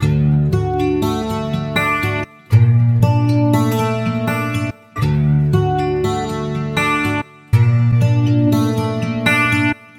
金属网被击中的MORPHAGENE
描述：地面上的金属网格被触摸并被击中。非标准化版本
Tag: 环境 金属 MOR phagene